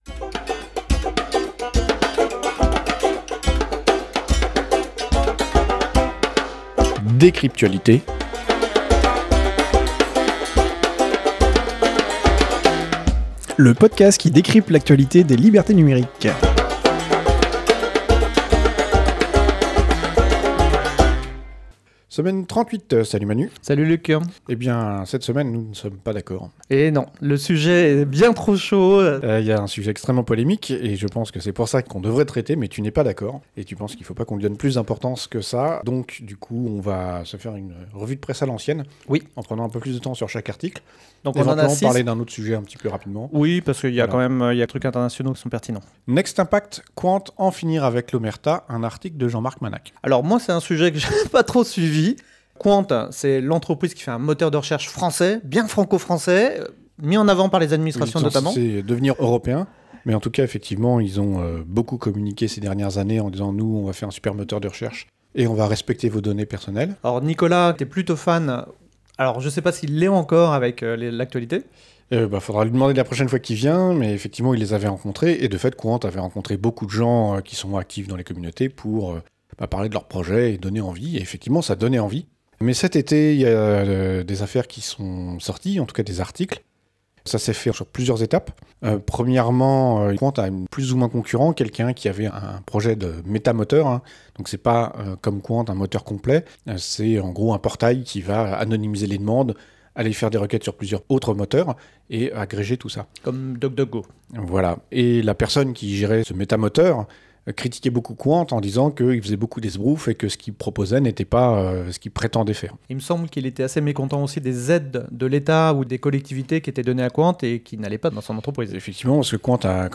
Une revue de presse à l'ancienne, le tour d'horizon des sujets de la semaine.